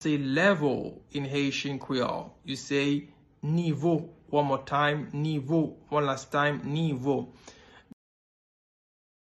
Pronunciation:
17.How-to-say-Level-in-Haitian-Creole-–-Nivo-pronunciation.mp3